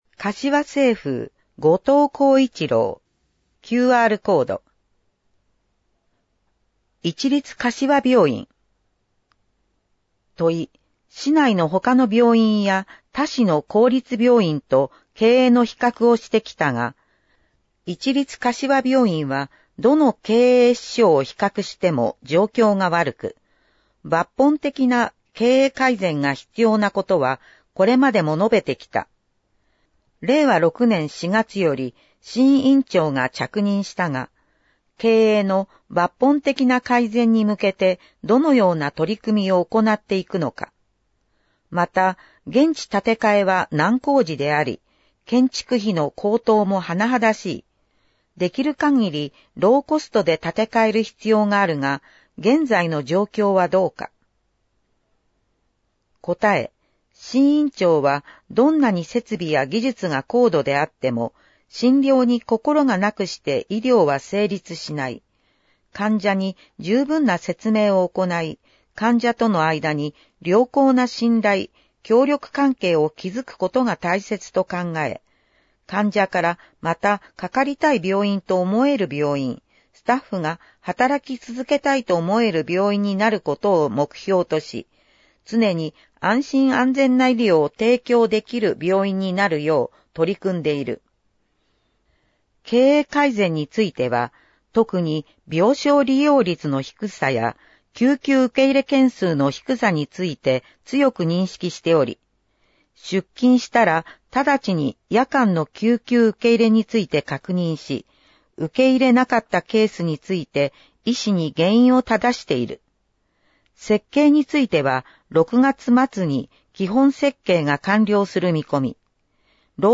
• かしわ市議会だよりの内容を音声で収録した「かしわ市議会だより音訳版」を発行しています。
• 発行は、柏市朗読奉仕サークル（外部サイトへリンク）にご協力いただき、毎号行っています。